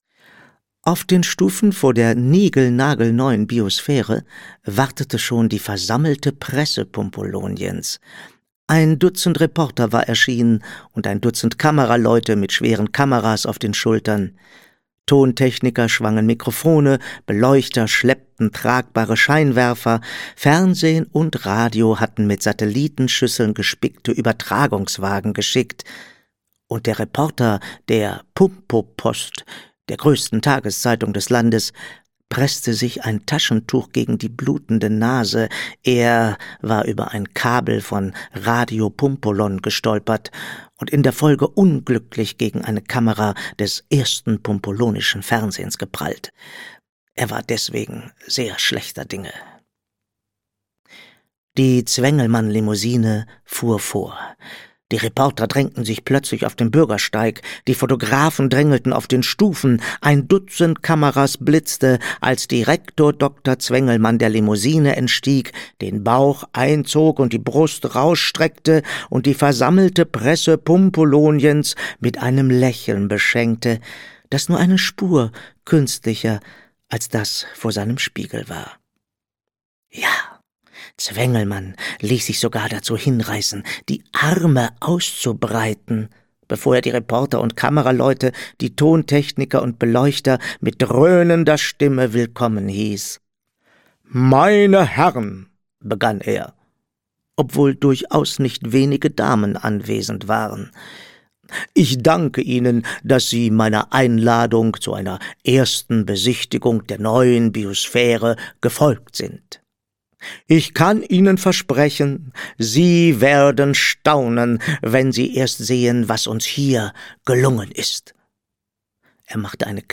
Alle lieben Urmel - Sabine Ludwig - Hörbuch